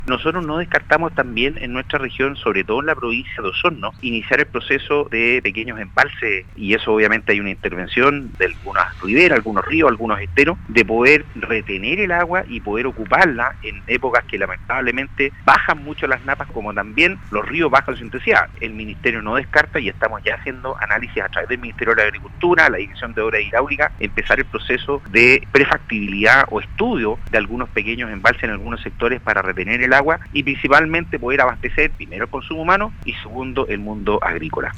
En entrevista con Radio Sago, el Seremi de Obras Públicas James Fry, explicó que con esta modificación se priorizará el consumo humano y después el uso de agua para producción agrícola.